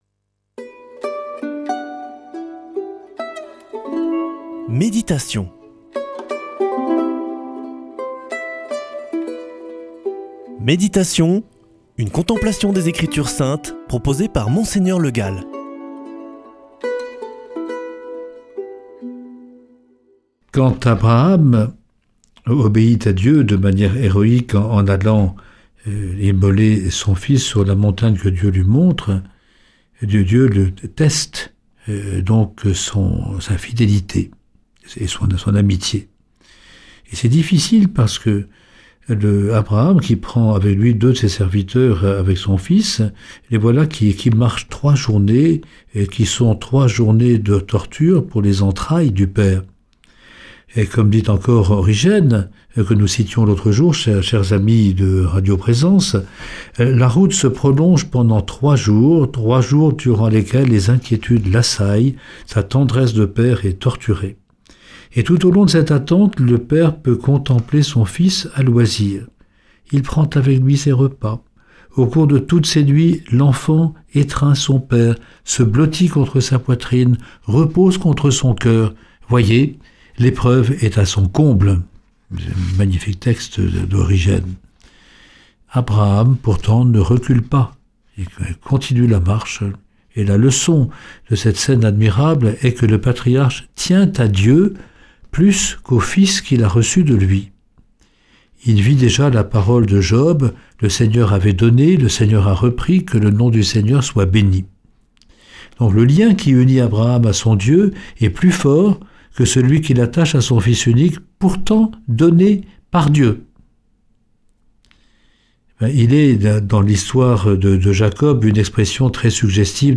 Méditation avec Mgr Le Gall
Une émission présentée par
Monseigneur Le Gall